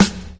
07_Snare_18_SP.wav